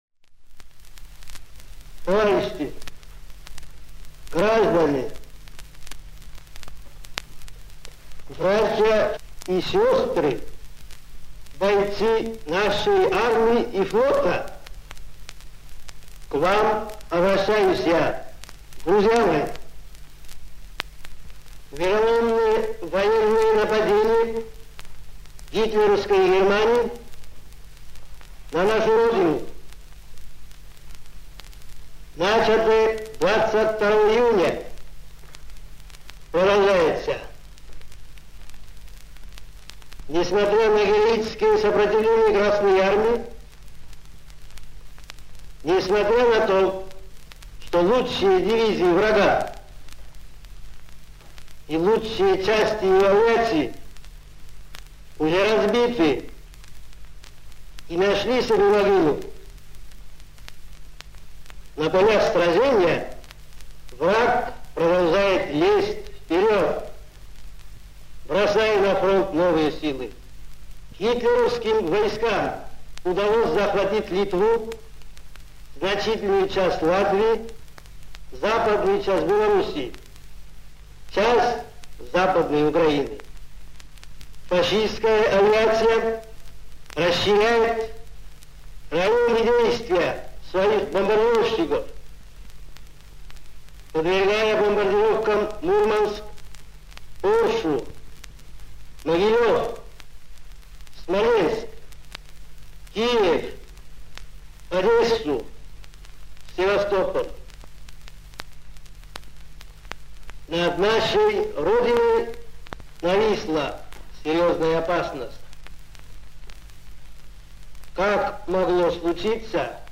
Выступление по радио Председателя Государственного Комитета Обороны И. В. Сталина 3 июля 1941 года.
Stalin_rech_3jul.mp3